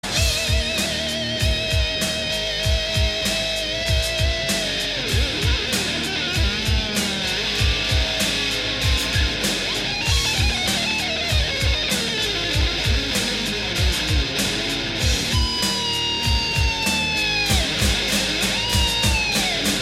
The guitar solo